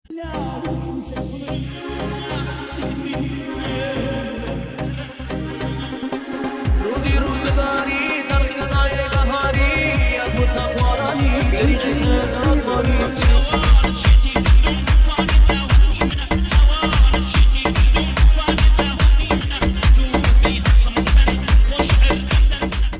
Instagram music